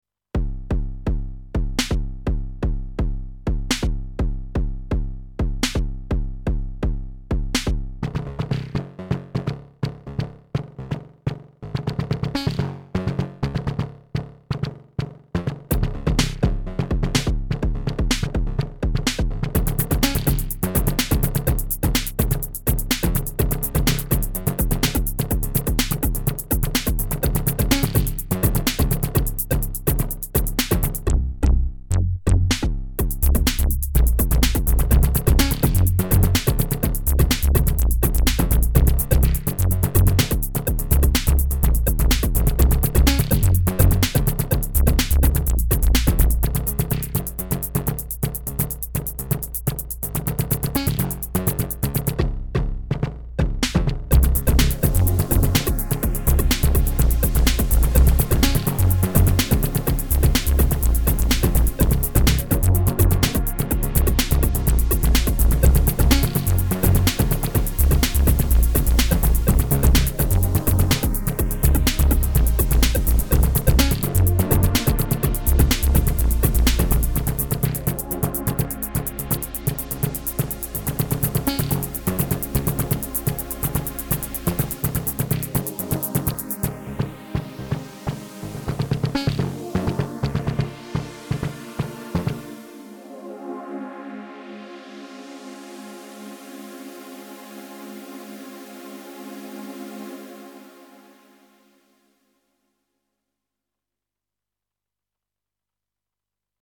only uses the drum timbre (except for the strings) on Korg Radias.